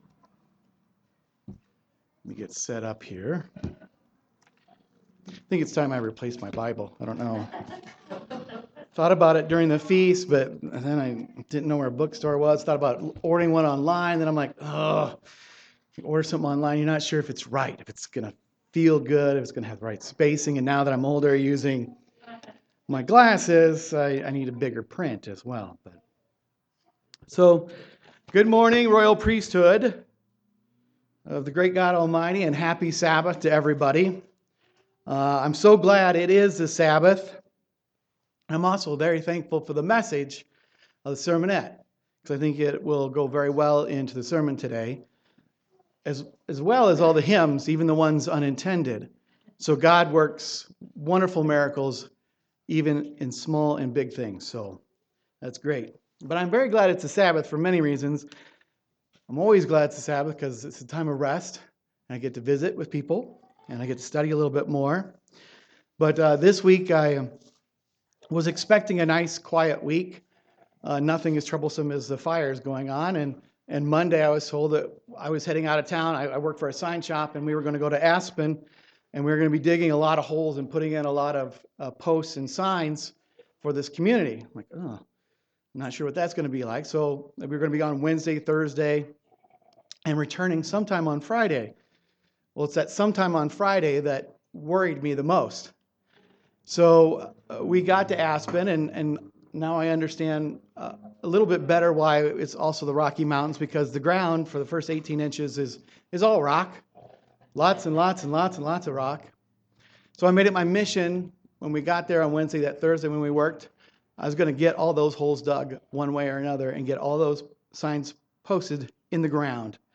Sermons
Given in Colorado Springs, CO Denver, CO